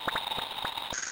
科幻空间引擎
描述：科幻空间引擎，发动机“嗡嗡”声。
标签： 空间 科幻 FX 引擎 等离子体
声道立体声